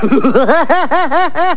krustylaf.wav